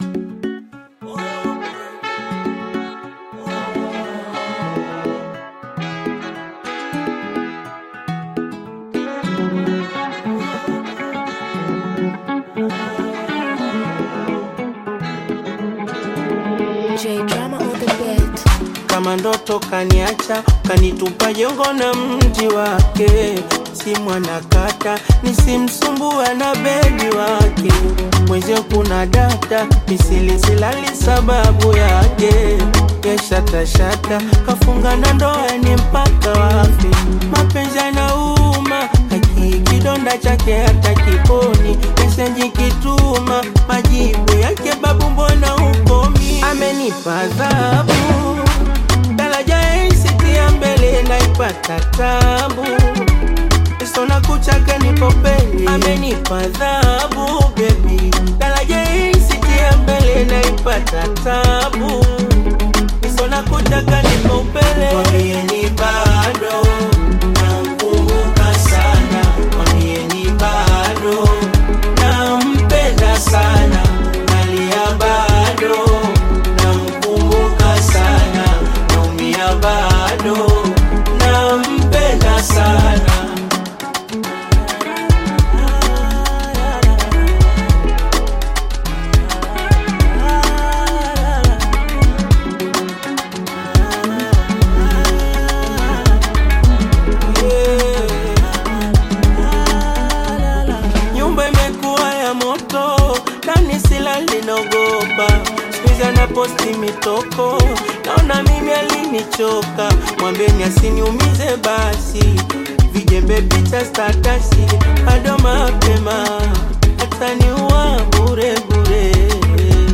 Bongo Flava music track
Tanzanian Bongo Flava artist, singer, and songwriter
Bongo Flava song